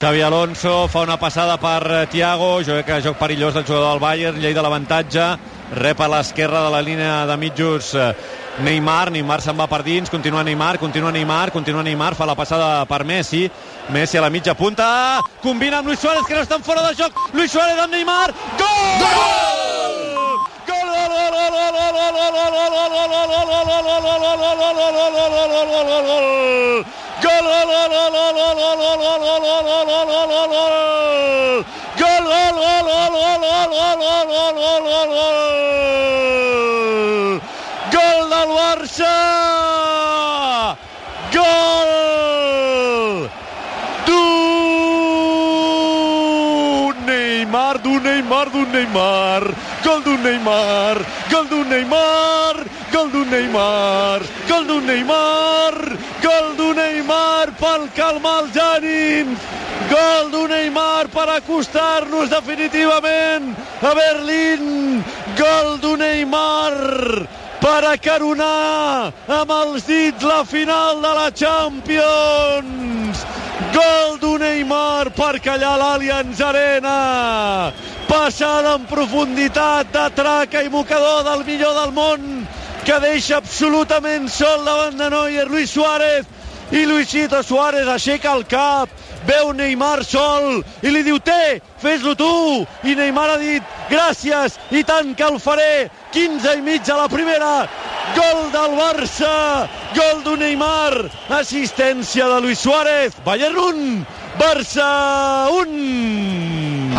Transmissió del partit de tornada de la fase eliminatòria de la Copa d'Europa de futbol masculí entre el Bayern München i el Futbol Club Barcelona.
Narració del primer gol del Futbol Club Barcelona, marcat per Neymar. Comentari i reconstrucció de la jugada.
Esportiu